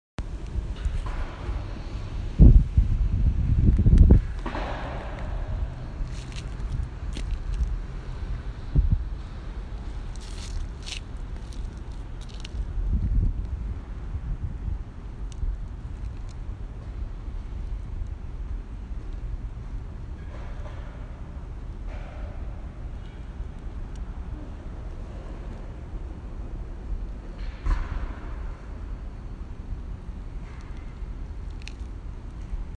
Mineola Train Station (Sunday Night)
Skateboarding, rain, wind
Field Recording